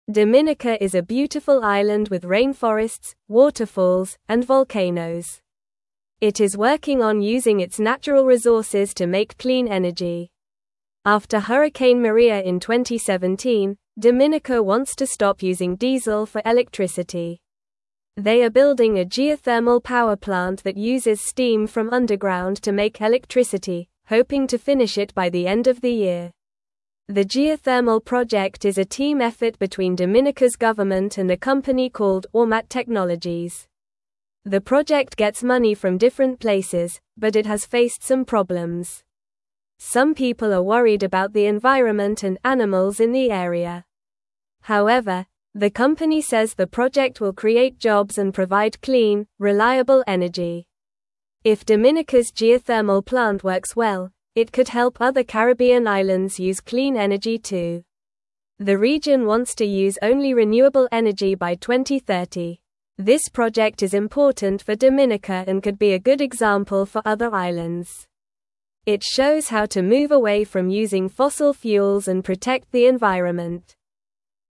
Normal
English-Newsroom-Lower-Intermediate-NORMAL-Reading-Dominicas-Clean-Energy-Plan-for-a-Bright-Future.mp3